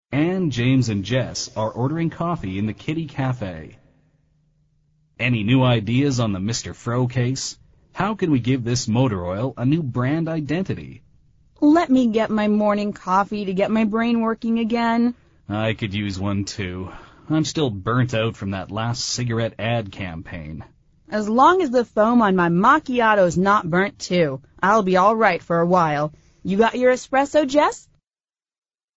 美语会话实录第163期(MP3+文本):I'm burnt out!